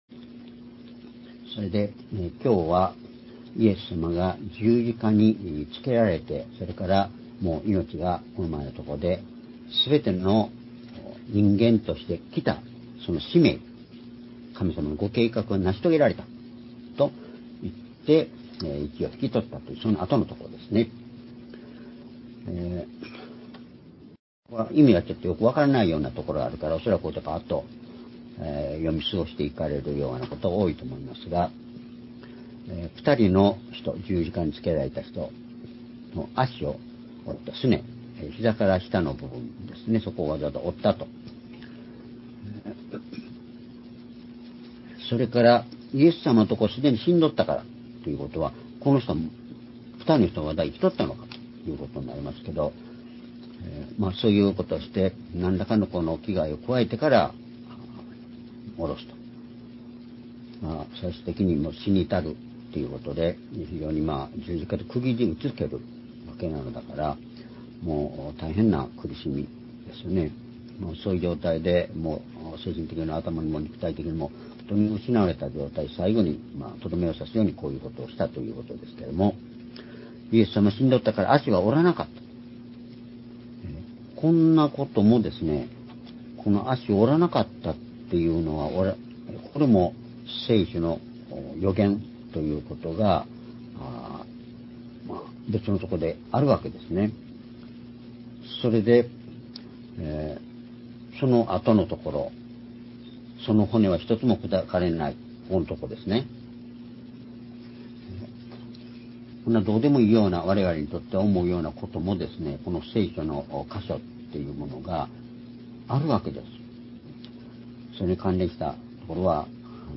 「聖書の言葉は実現する」ヨハネ19章31～37節-2025年2月23日(主日礼拝)
主日礼拝日時 2025年2月23日(主日礼拝) 聖書講話箇所 「聖書の言葉は実現する」 ヨハネ19章31～37節 ※視聴できない場合は をクリックしてください。